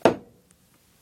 工具 " 锤击5
描述：几个击中（在木头上）用中型锤子。录制第四代iPod touch，然后使用media.io转换为.wav
声道立体声